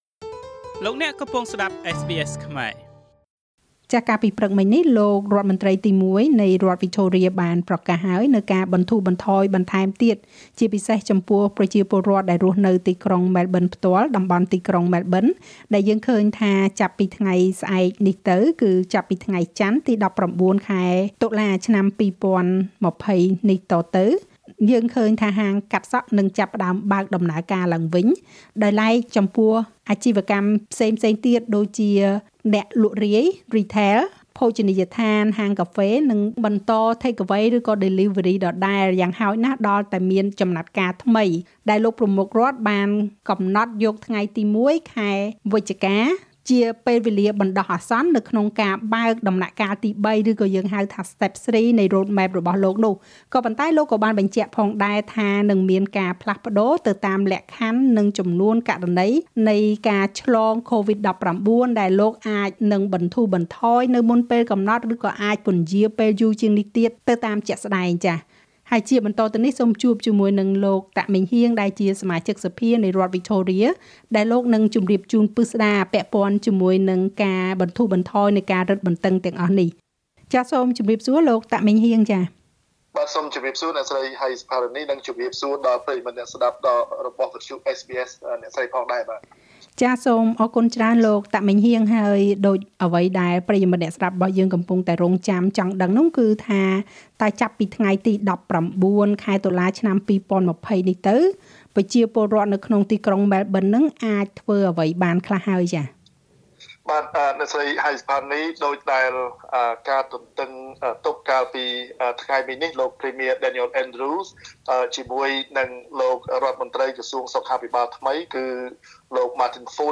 នៅថ្ងៃចន្ទទី១៩ ខែតុលានេះ អ្នករស់នៅក្នុងទីក្រុងម៉ែលប៊ន នឹងអាចធ្វើដំណើរចេញពីផ្ទះបានរហូតដល់ទៅ២៥គីឡូម៉ែត្រ ក្នុងហេតុផលចាំបាច់៤យ៉ាង។ ហាងកាត់សក់នឹងបើកដំណើរការឡើងវិញ ប៉ុន្តែអាជីវកម្មលក់រាយ ភោជនីយដ្ឋាន ហាងកាហ្វេ នឹងត្រូវរង់ចាំការបន្ធូរបន្ថយជំហានទី៣ជាបន្តទៀត។ លោកសមាជិកសភា តាក ម៉េងហ៊ាង ពន្យល់បន្ថែម។